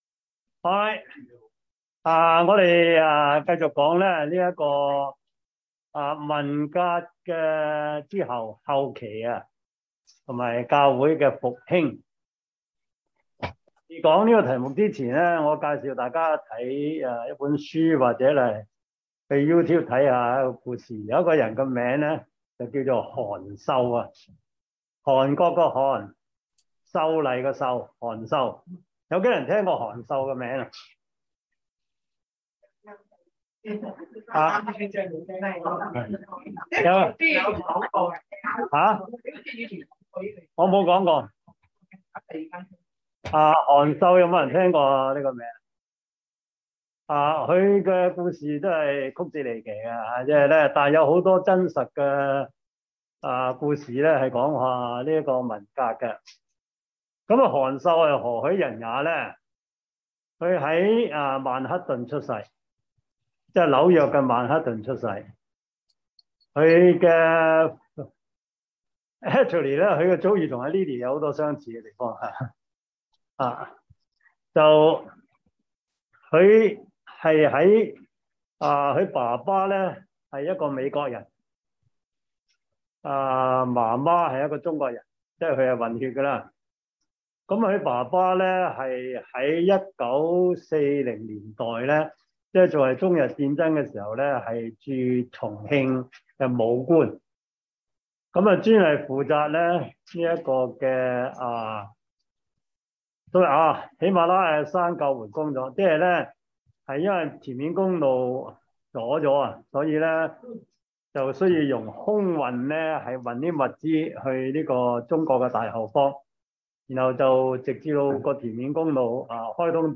教會歷史 Service Type: 中文主日學 中國教會史